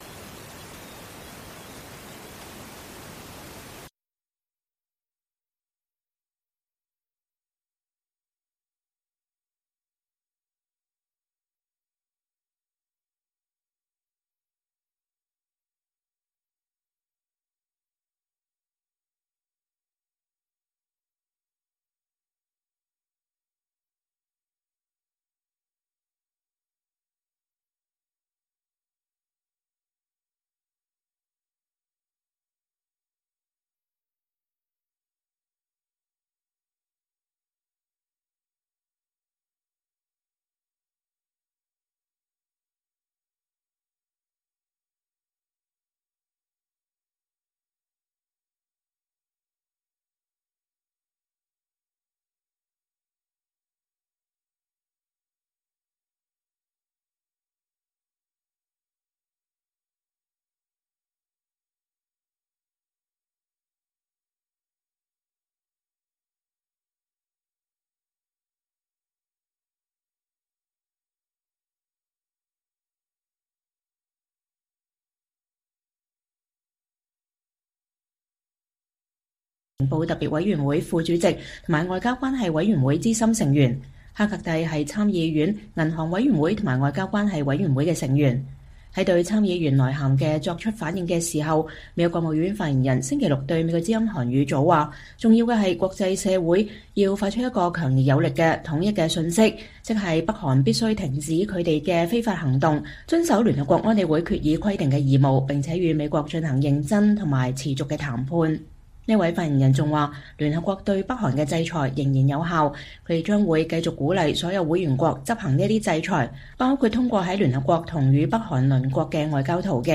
粵語新聞 晚上9-10點：美國參議員敦促拜登加大對北韓施壓力度